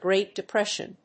アクセントGréat Depréssion
音節Grèat Depréssion